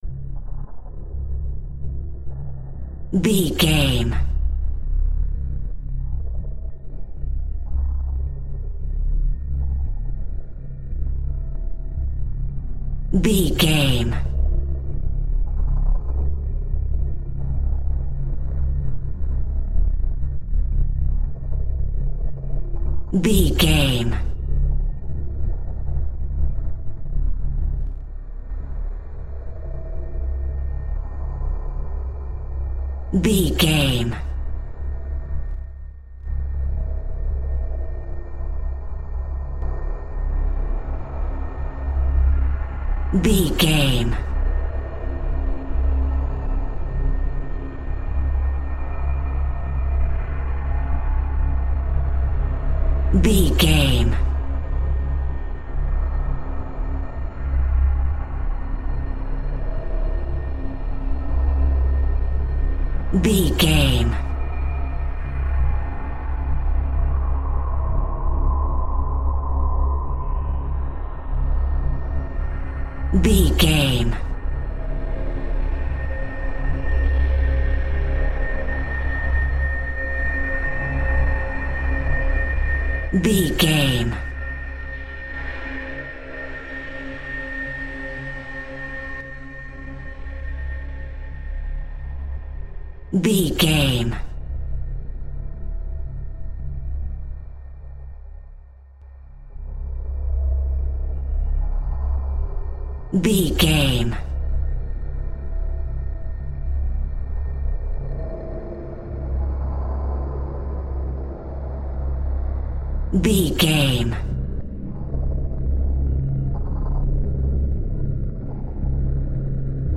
Atonal
F#
Slow
scary
ominous
haunting
eerie
synthesiser
instrumentals
horror music
Horror Pads
horror piano
Horror Synths